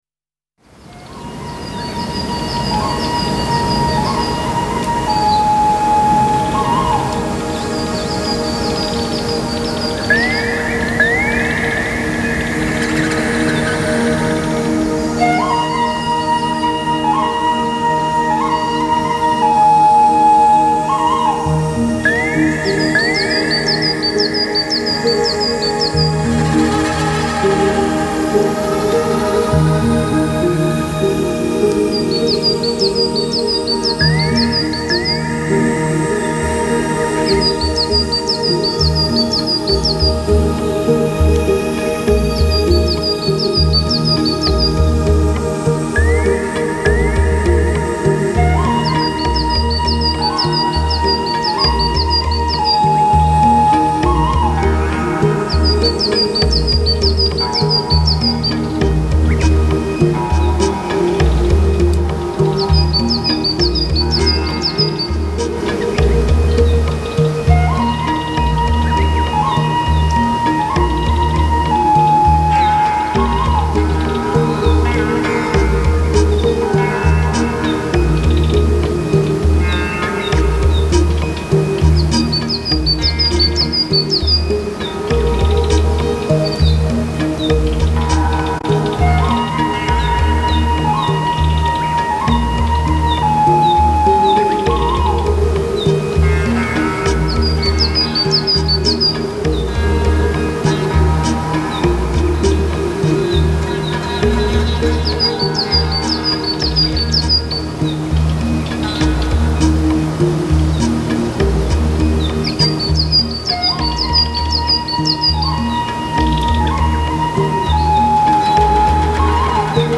早春冰雪消融的涓涓溪流声
夏日山雨过后的雨滴声
秋天落叶的缤纷簌簌之声
根据钢琴、吉他、排箫、凤笛、
二胡等各种乐器的不同音色及所带来的情绪感受，